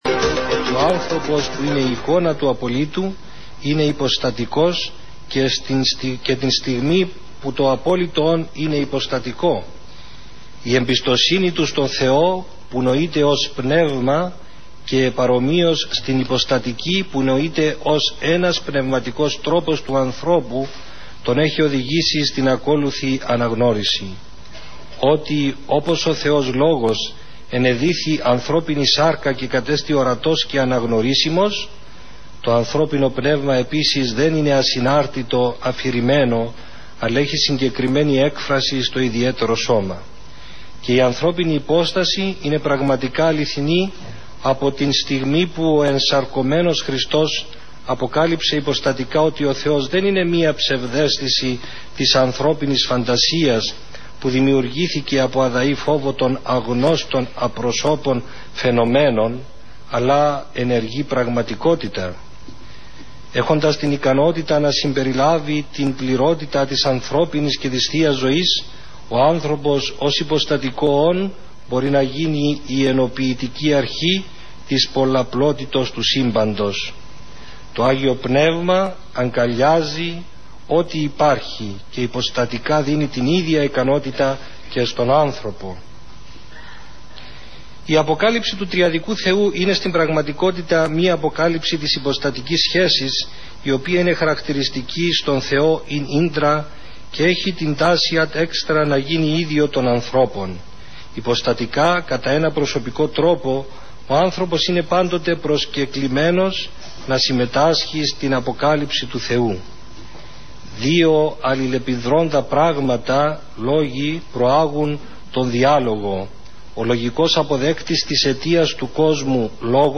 Ακολουθούν ηχογραφημένα αποσπάσματα του συνεδρίου από τον ραδιοφωνικό σταθμό Πειραϊκή Εκκλησία 91,2 FM.